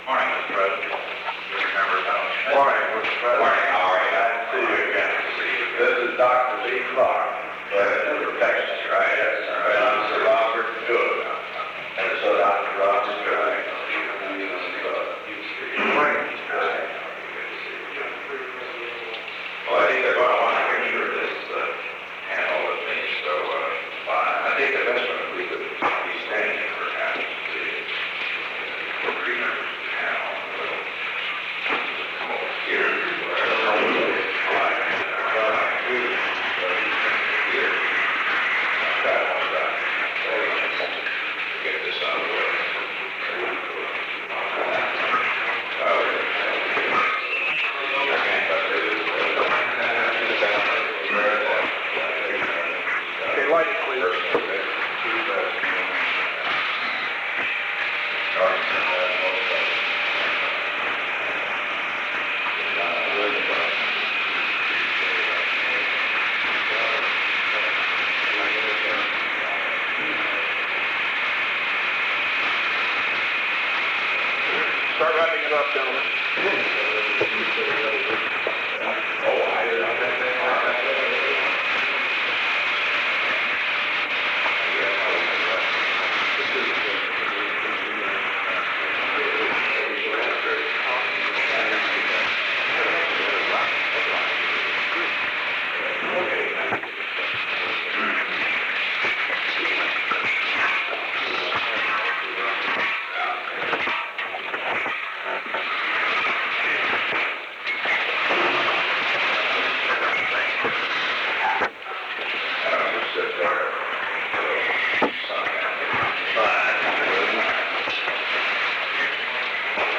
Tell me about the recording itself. Recording Device: Oval Office The Oval Office taping system captured this recording, which is known as Conversation 660-017 of the White House Tapes.